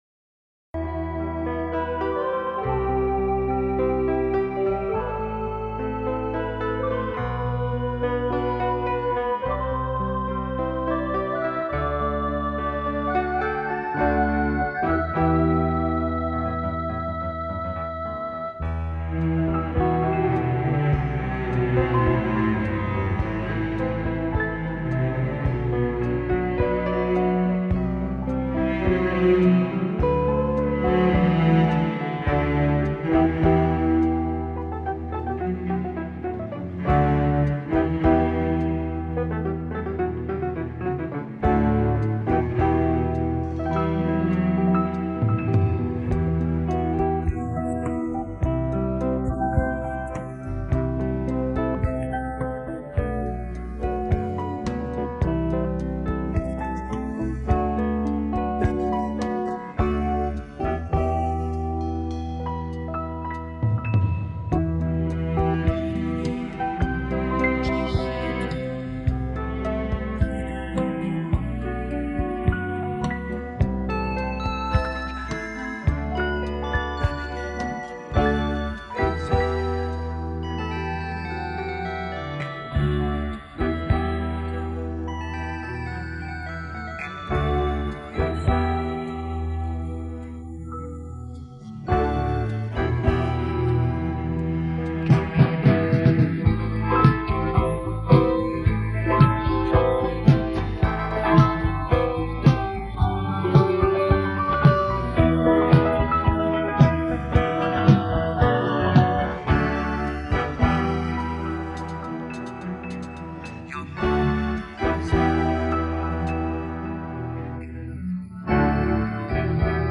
download-cloud دانلود نسخه بی کلام (KARAOKE)